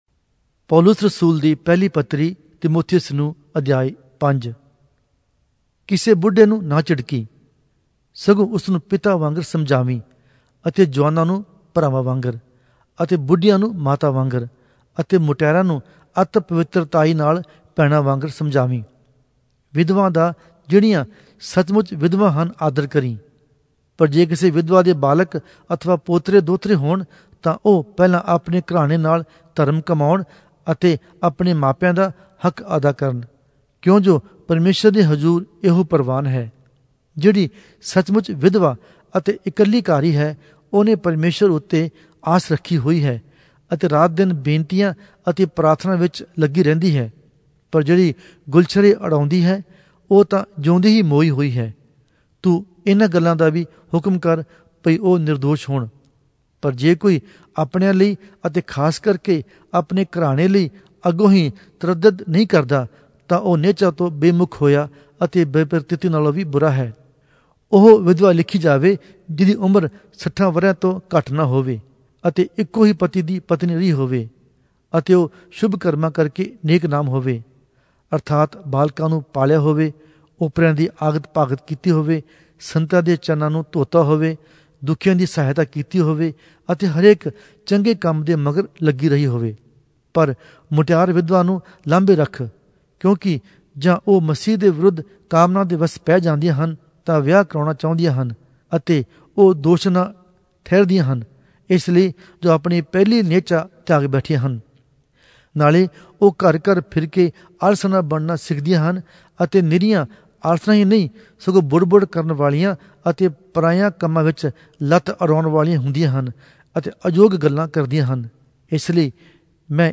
Punjabi Audio Bible - 1-Timothy 2 in Lxxen bible version